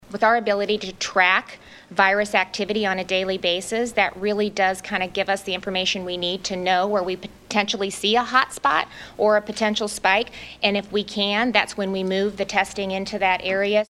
The new case count includes an increase of more than 400 cases in northwest Iowa’s Buena Vista County alone. Moments ago, Governor Kim Reynolds was asked if there’s an outbreak at a food production facility in Storm Lake — and she said nothing is confirmed: